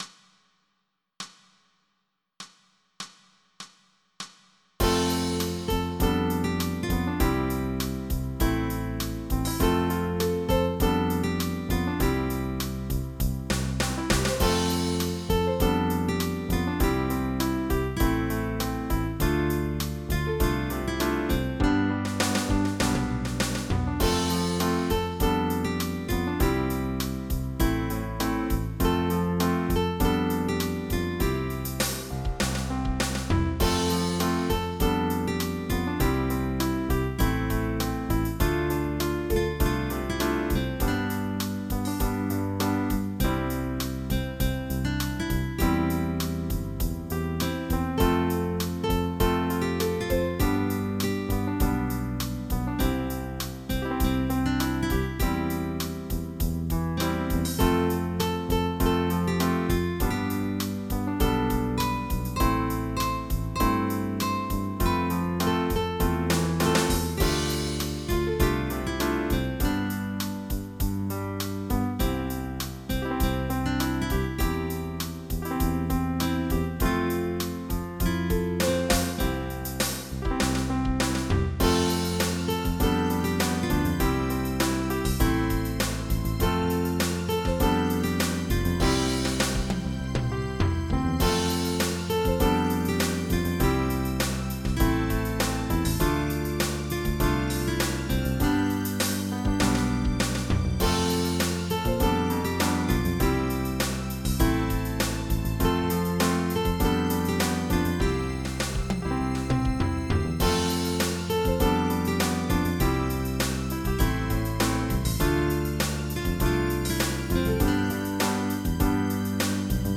MIDI Music File
General MIDI